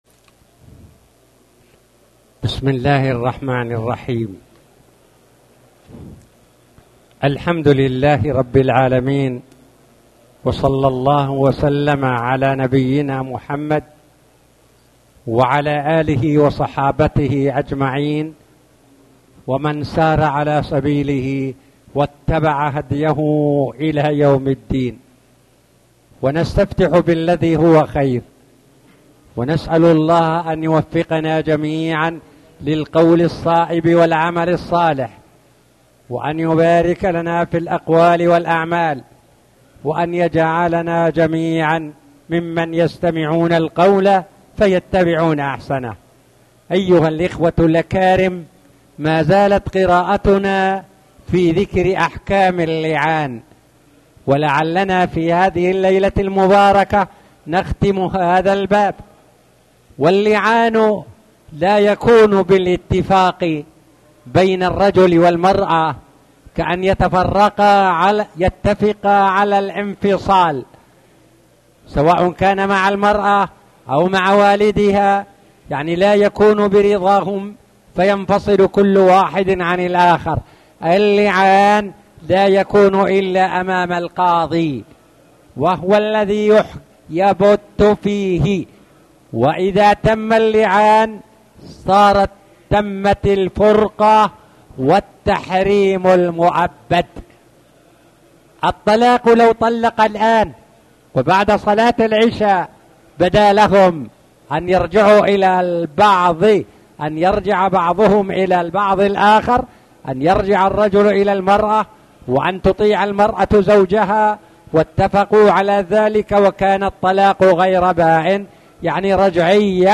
تاريخ النشر ٧ محرم ١٤٣٩ هـ المكان: المسجد الحرام الشيخ